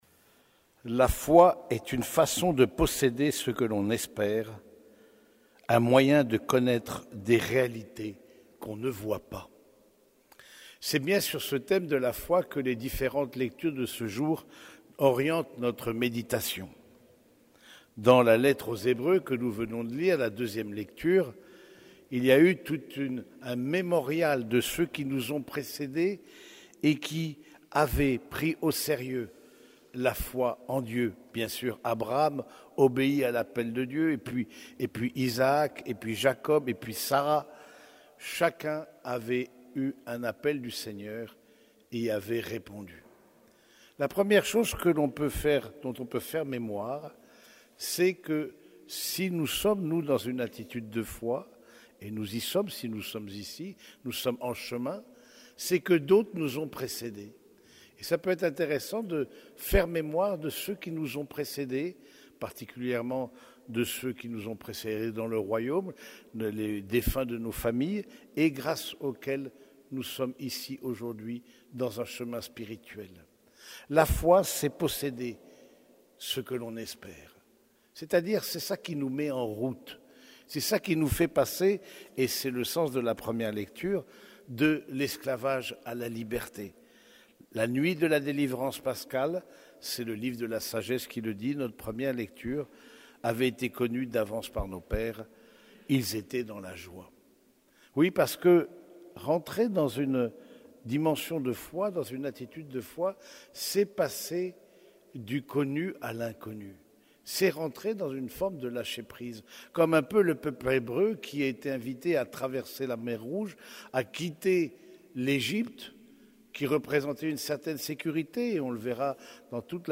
Homélie du 19e dimanche du Temps Ordinaire